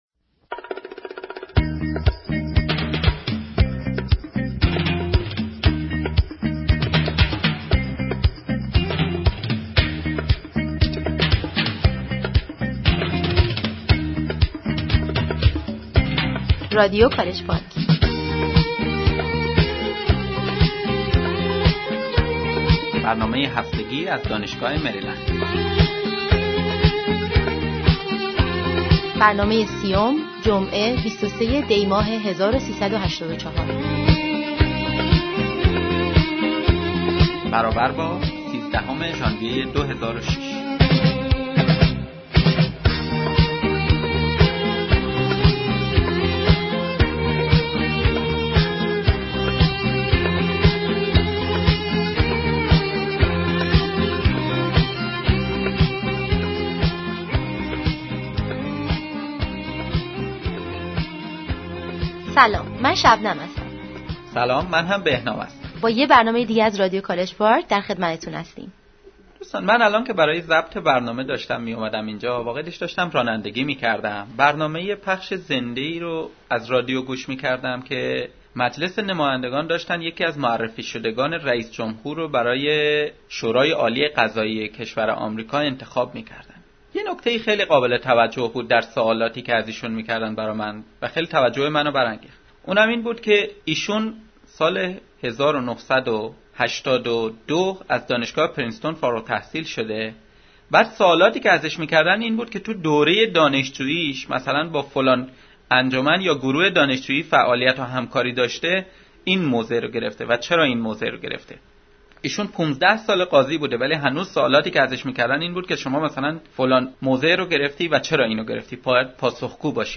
A report about horse riding in Tehran